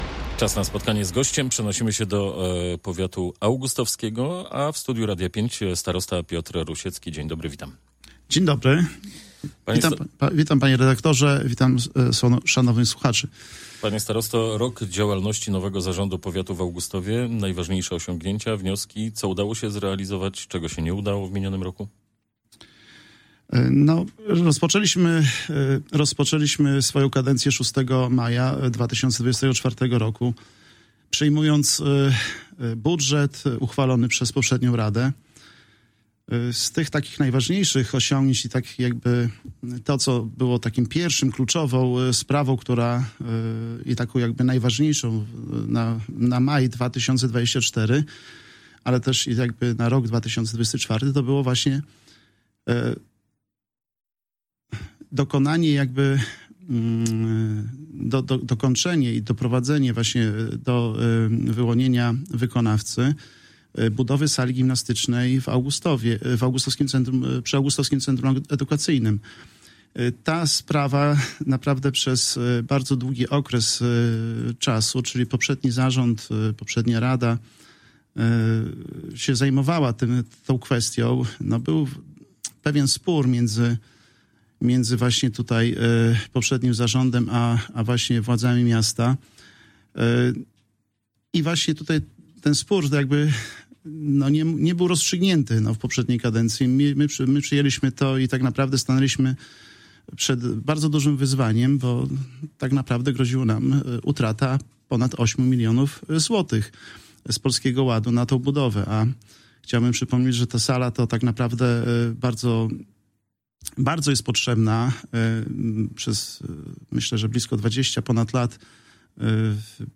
O prowadzonych inwestycjach, pracach drogowych i powiatowej służbie zdrowia mówił w Magazynie Powiatu Augustowskiego Radiu 5 Piotr Rusiecki, starosta powiatu augustowskiego. Było między innymi o budowie sali sportowej przy ACE.